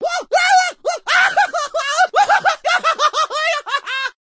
russel scream htf
russel-scream-htf.mp3